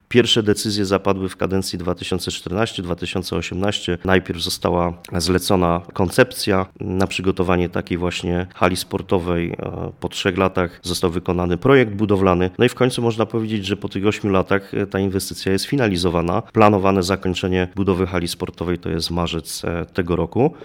Tymczasem, starania o jej budowę rozpoczęły się już wiele lat temu i wymagały naprawdę mnóstwa starań, mówi nasz dzisiejszy gość starosta żywiecki Adrian Midor.